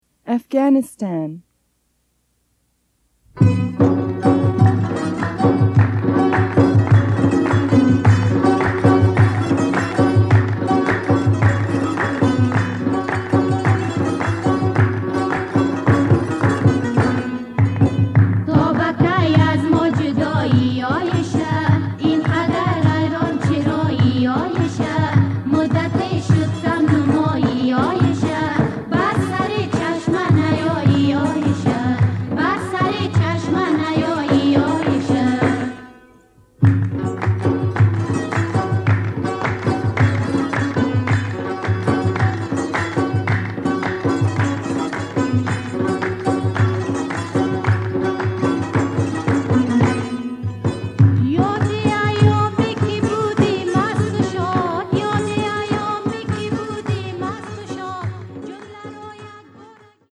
mp3はラジカセ(東芝 TY-CDX92)で作成しました。
mp3音源サンプル(A面冒頭)